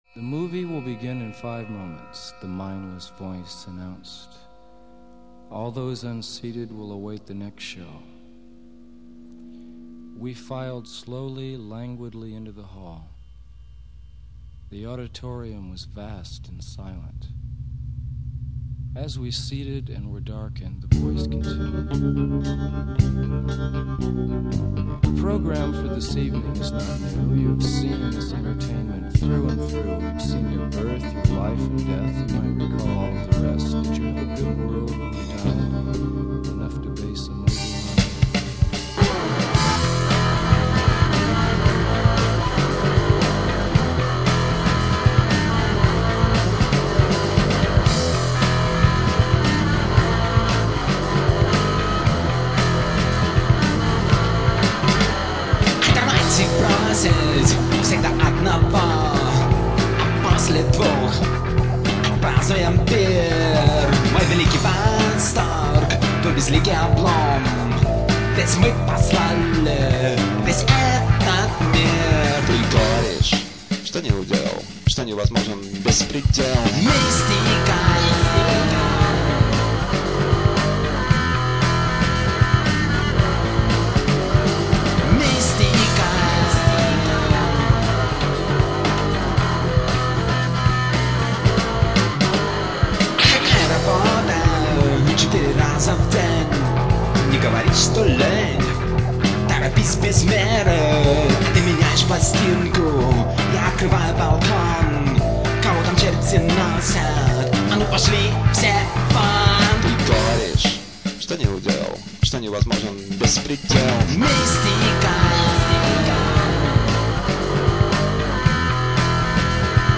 Альтернативная (2891)